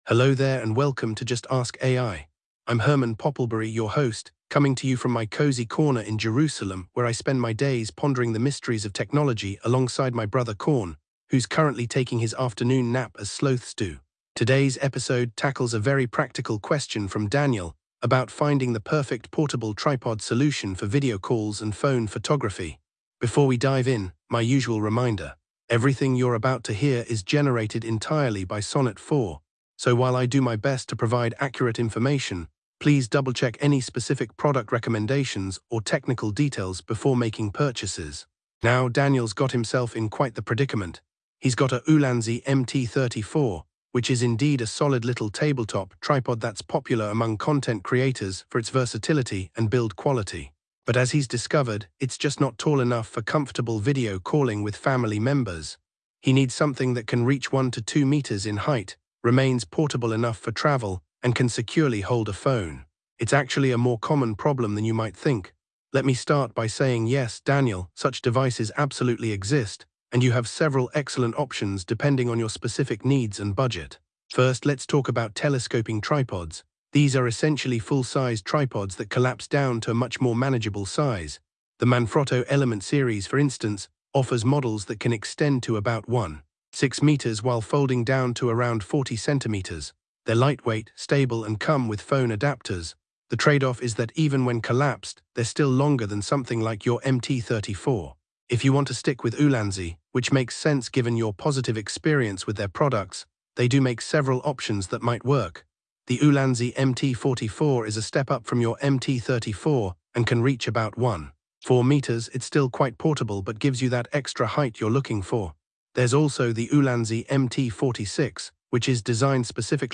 AI-Generated Content: This podcast is created using AI personas.
Hosts Herman and Corn are AI personalities.